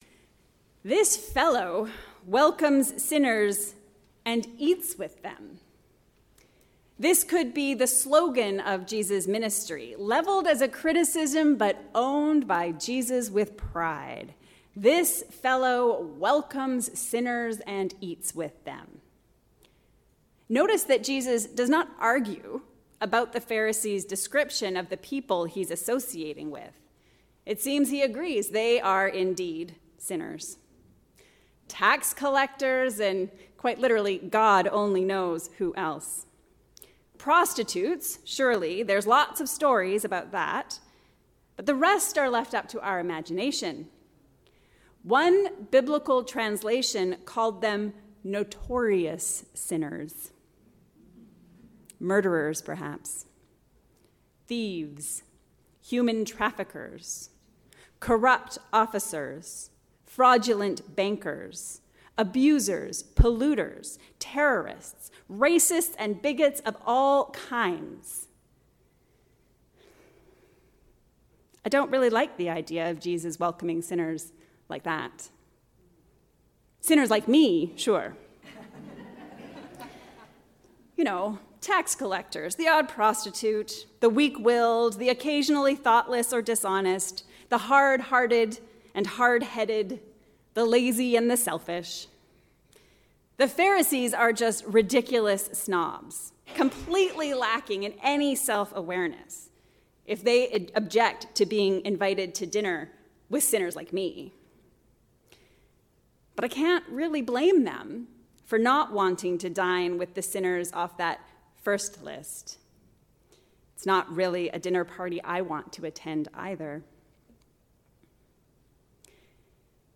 Found, Forgiven, and Free. A sermon on Luke 15:1-10